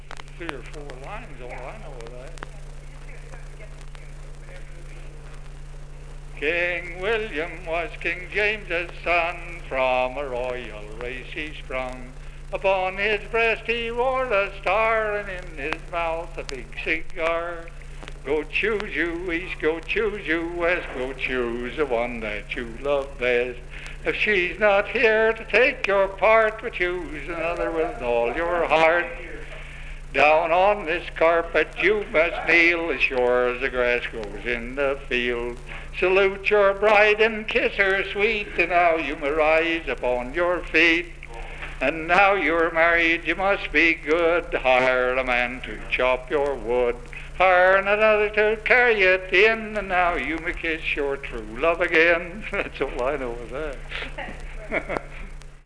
戀歌 (Sweetheart Song)
Other songs offered advice to children on love and marriage, such as "King William Was King James' Son." This is a folk song from Northern California during the time of California's second Gold Rush in the 1930s.